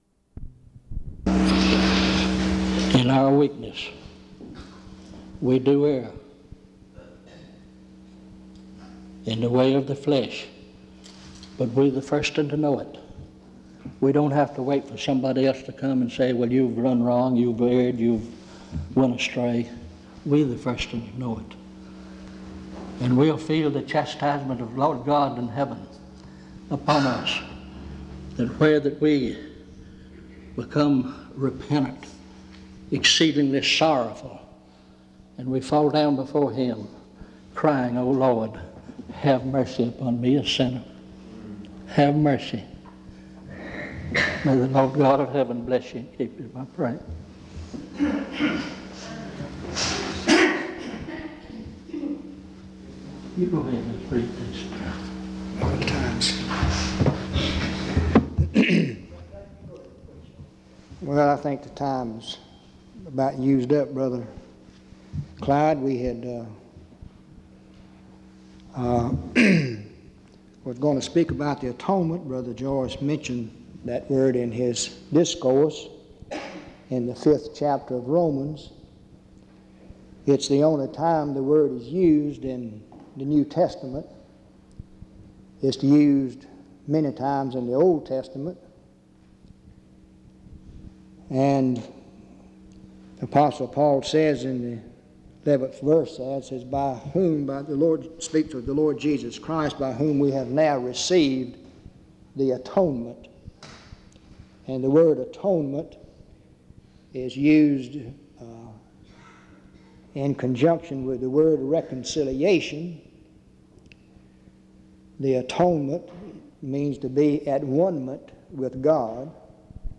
In Collection: Reidsville/Lindsey Street Primitive Baptist Church audio recordings Thumbnail Titolo Data caricata Visibilità Azioni PBHLA-ACC.001_040-A-01.wav 2026-02-12 Scaricare PBHLA-ACC.001_040-B-01.wav 2026-02-12 Scaricare